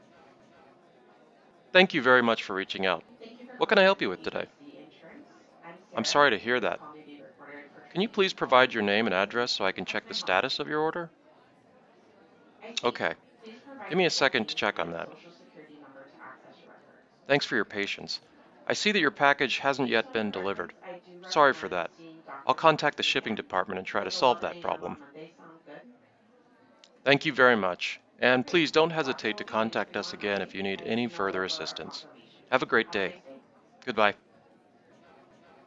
bgvoice-before.mp3